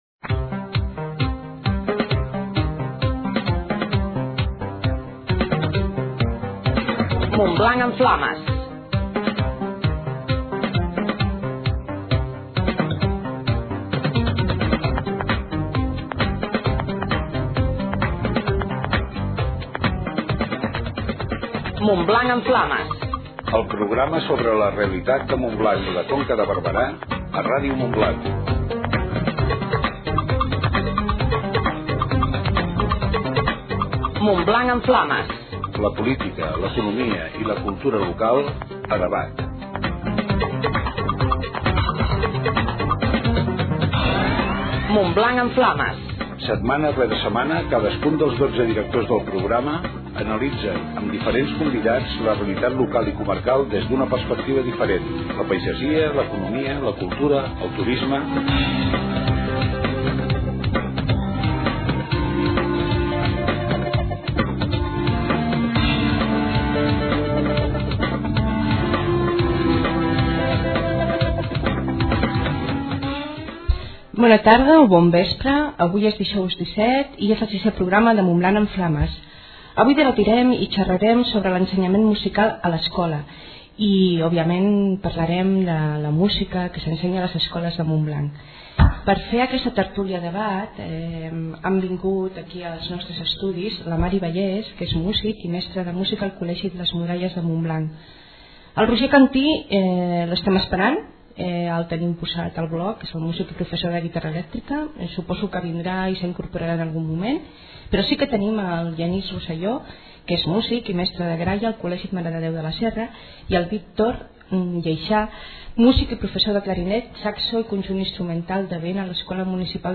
La Casa de Palla es trasllada per un dia al programa debat de Ràdio Montblanc, Montblanc en Flames.